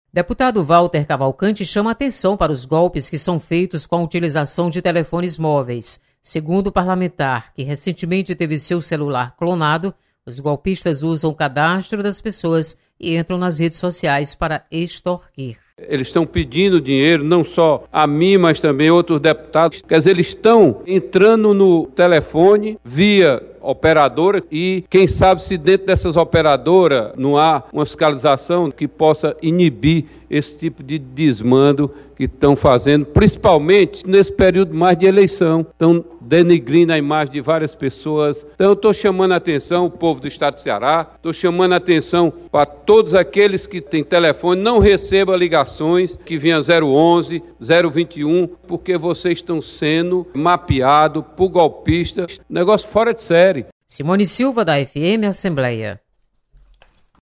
Deputado Walter Cavalcante alerta para golpes praticados contra usuários de telefones móveis. Repórter